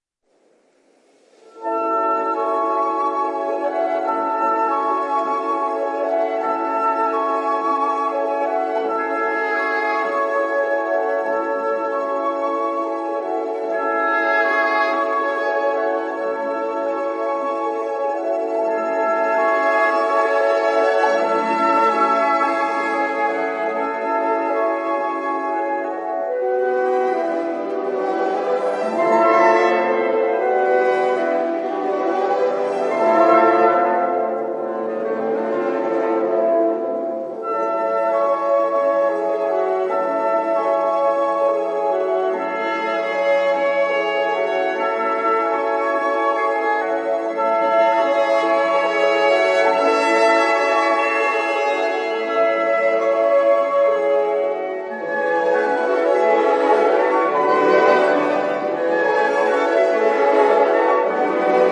Tr�s souple de rythme